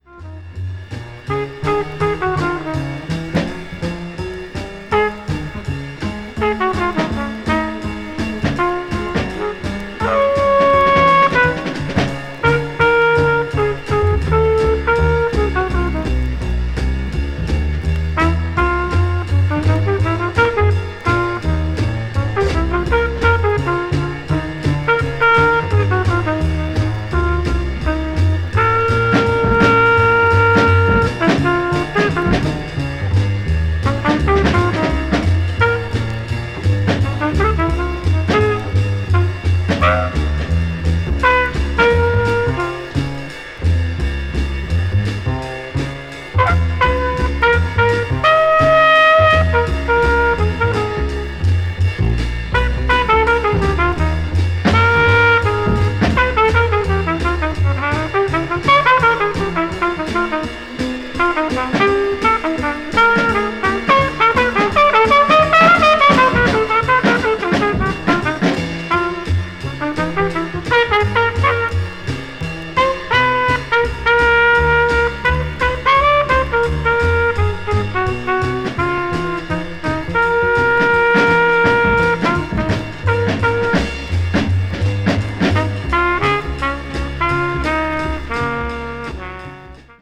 avant-jazz   free jazz   post bop